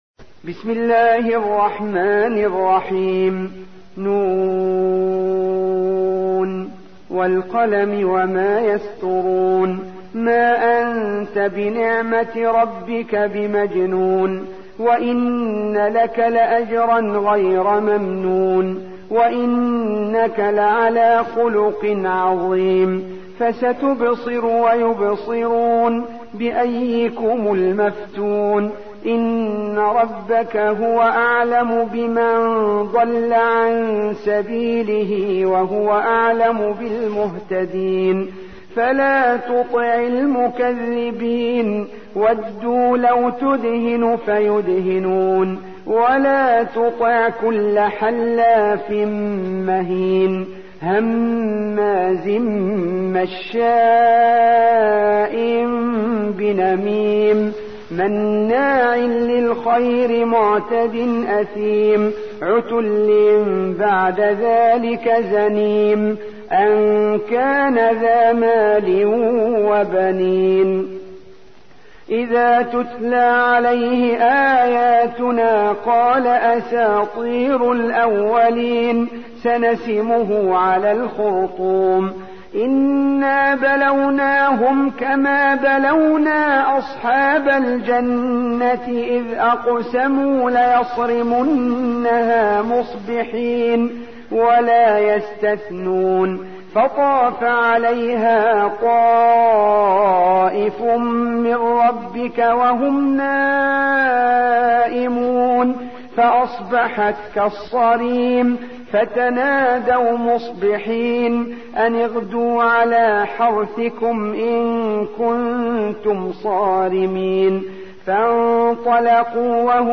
68. سورة القلم / القارئ